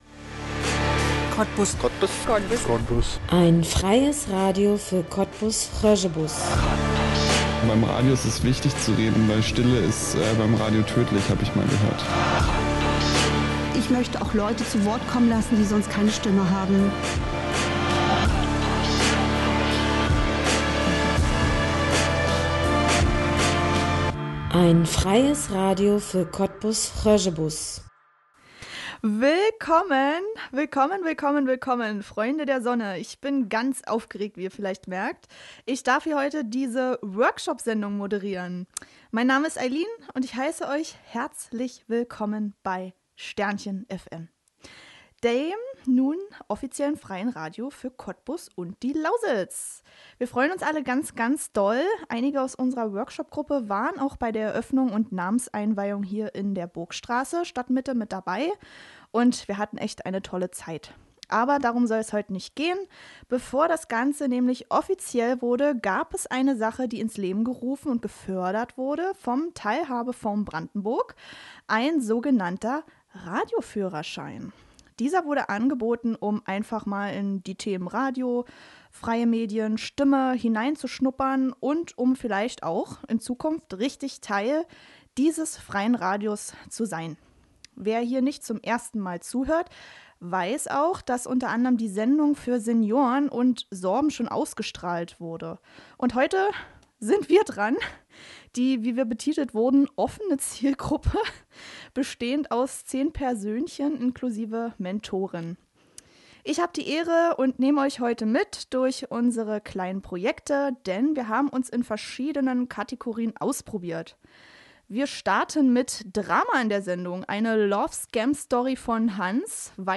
Sondersendung - Magazinsendung der offenen Workshopzielgruppe